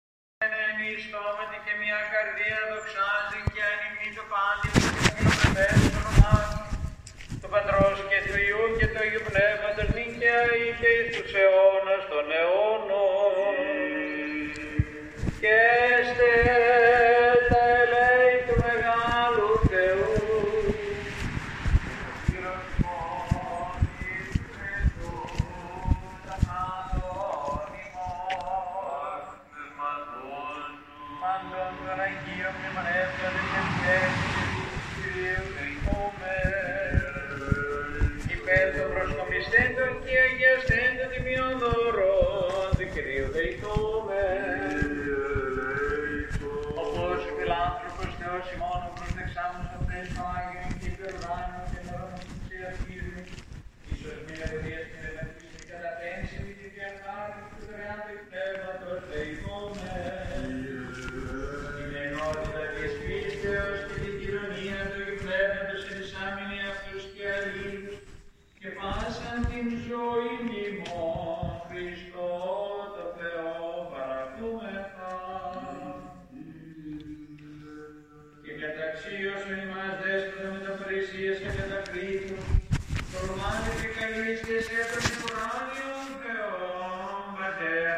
Gottesdienst im Gange. Da ich für den Cache eine eingravierte Jahreszahl an der Kirchentür benötigte, nahm ich vor der Tür - unterstützt von mehreren Lautsprechern - teil, wo schon eine Dame mit dem Handy beschäftigt war.
Gottesdienst.mp3